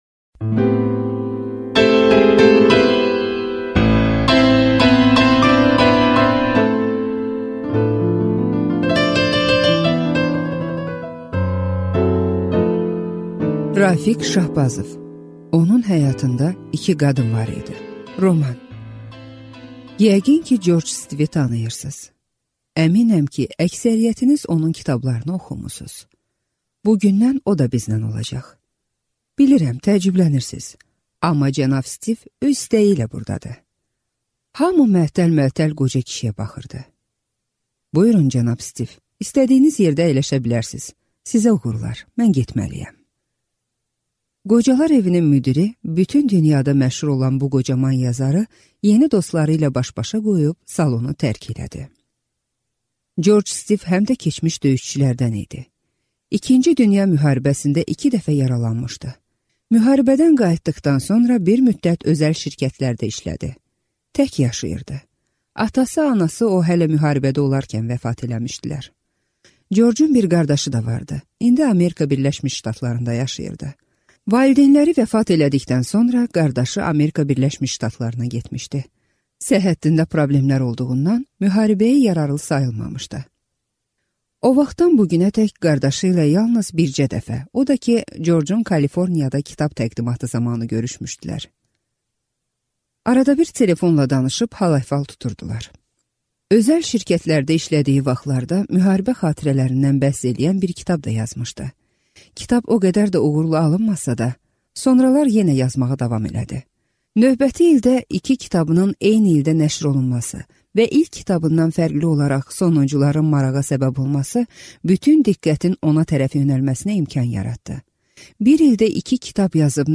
Аудиокнига Onun həyatında 2 qadın var idi | Библиотека аудиокниг